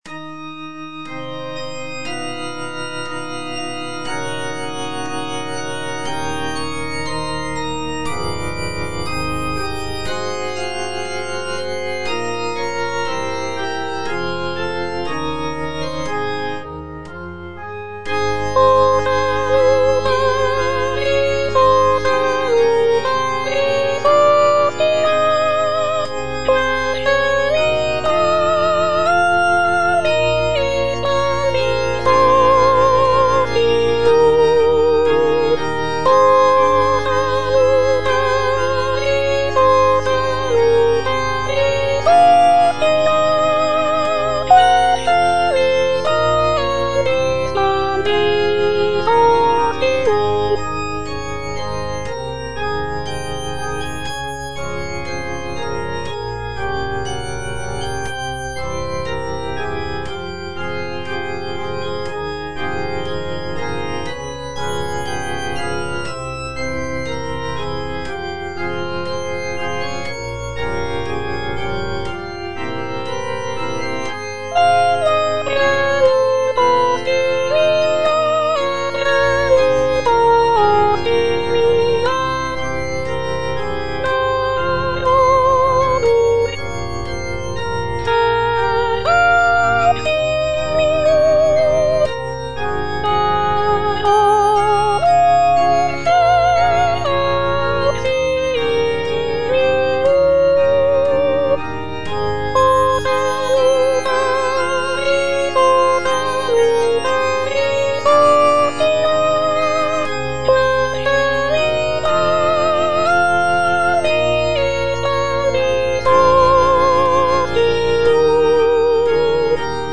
G. FAURÉ, A. MESSAGER - MESSE DES PÊCHEURS DE VILLERVILLE O salutaris (soprano I) (Voice with metronome) Ads stop: auto-stop